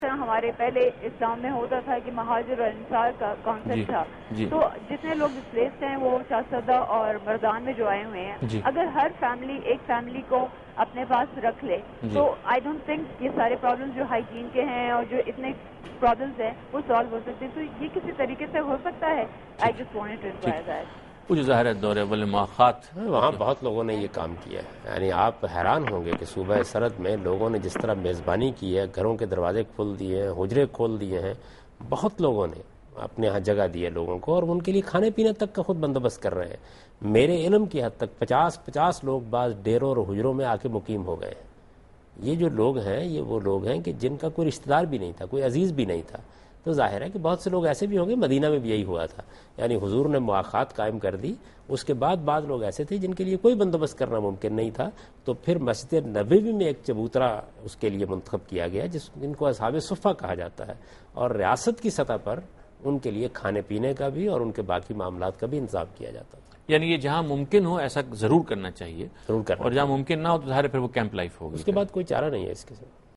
Answer to a Question by Javed Ahmad Ghamidi during a talk show "Deen o Danish" on Duny News TV